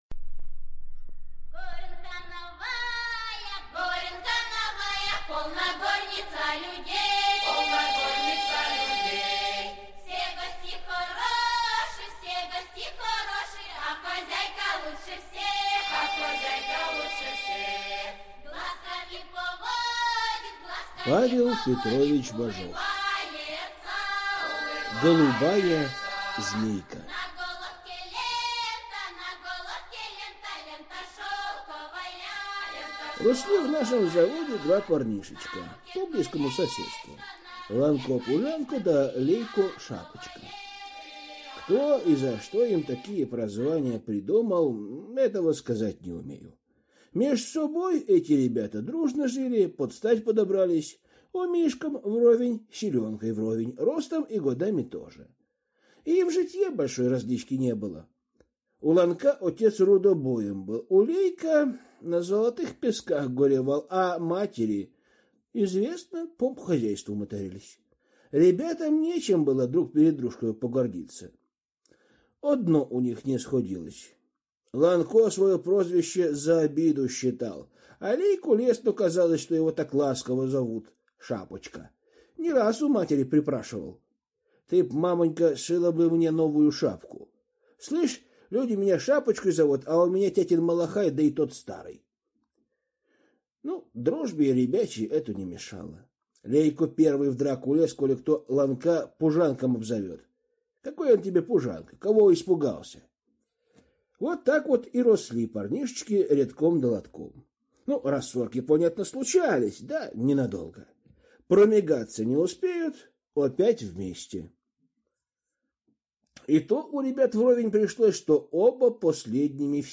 Аудиокнига Голубая змейка | Библиотека аудиокниг